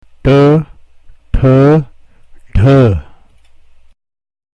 Though all the above sounds require that the tongue touch the top palate while being pronounced, the aspirations are such that the sounds come out "soft", and not hard like the earlier series of retroflexes
Thus the symbol T is pronounced the way one would pronounce the letter T in the name "TIBET".